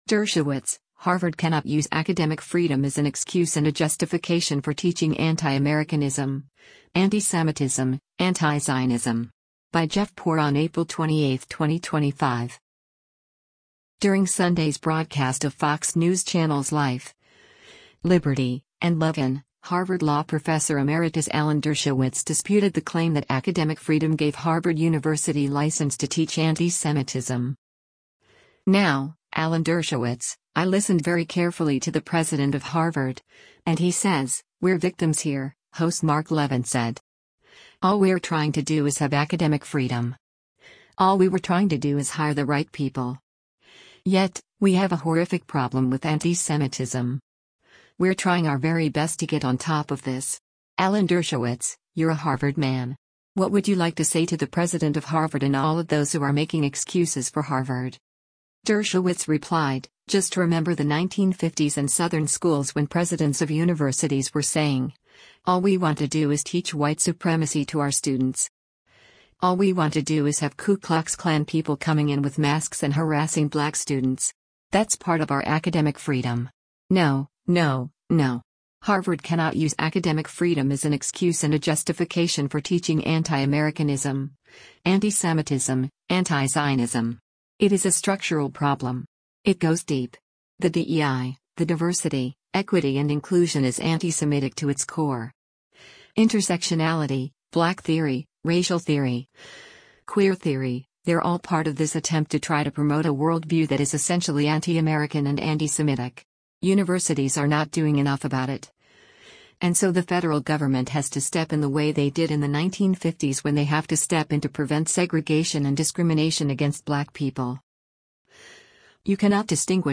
During Sunday’s broadcast of Fox News Channel’s “Life, Liberty & Levin,” Harvard Law professor emeritus Alan Dershowitz disputed the claim that academic freedom gave Harvard University license to teach antisemitism.